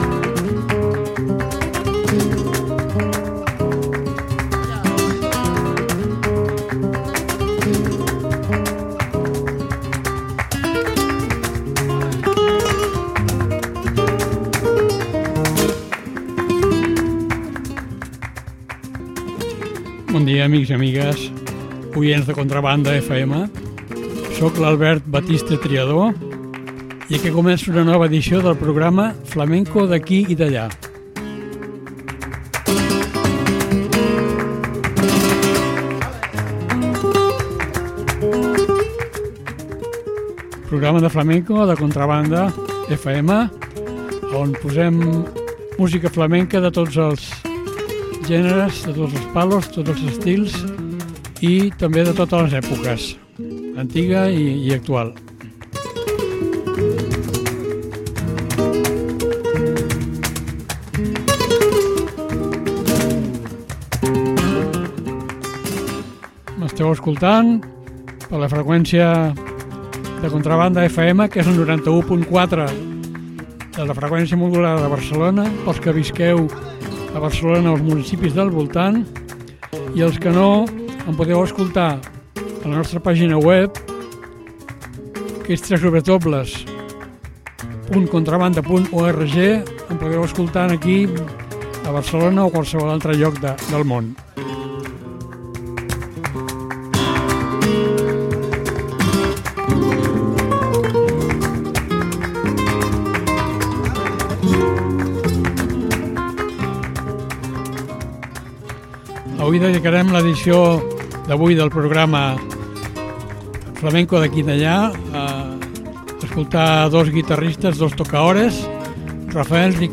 Programa en el que escoltarem a dos dels tocaores flamencos de guitarra solista més destacats. Rafael Riqueni, andalús de Sevilla. Juan Manuel Cañizares, català de Sabadell.